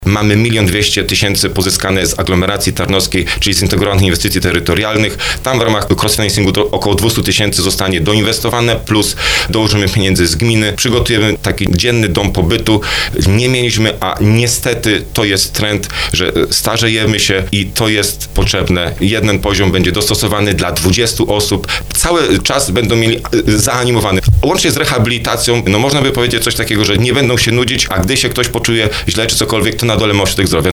Jak podkreśla wójt gminy Skrzyszów Marcin Kiwior, takie miejsce było w gminie bardzo potrzebne, a jego utworzenie pozwoli starszym mieszkańcom zarówno miło spędzić czas, jak i skorzystać z profesjonalnego wsparcia.